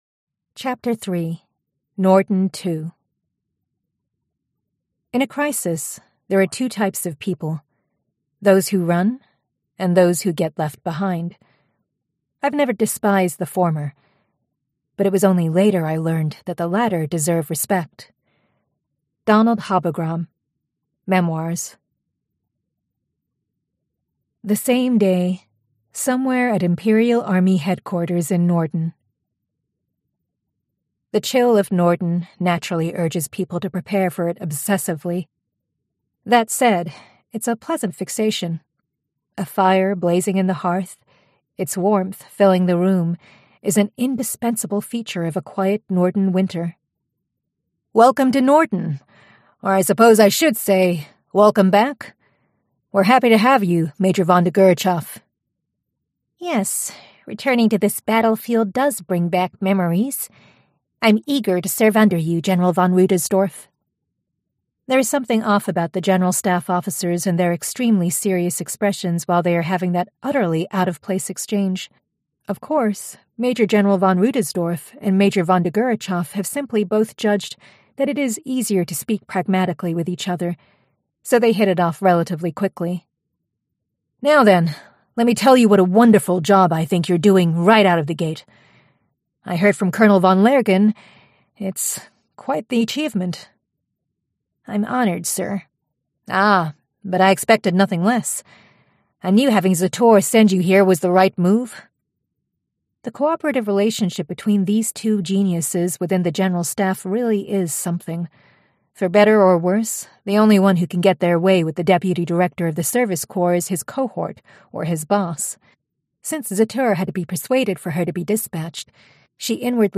[PZG] The Saga of Tanya the Evil, Vol. 02: Plus Ultra (Audiobook) [Yen Audio]